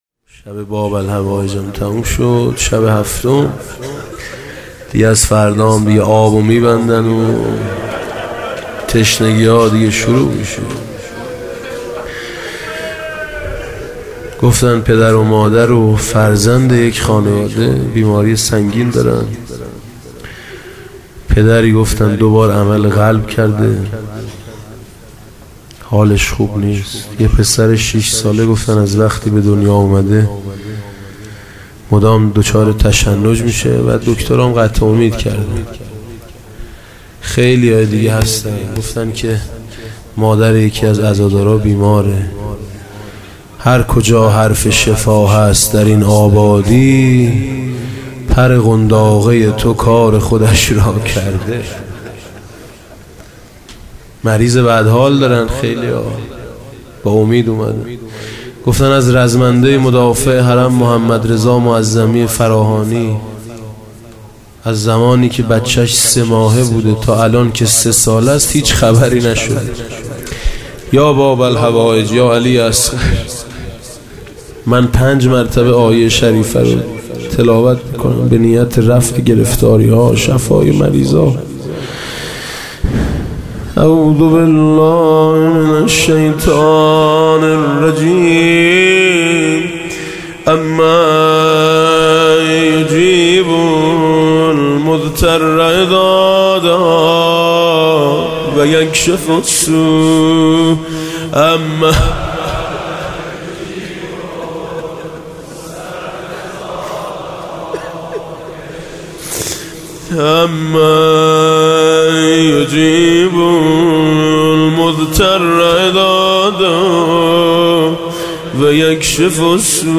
دعای پایانی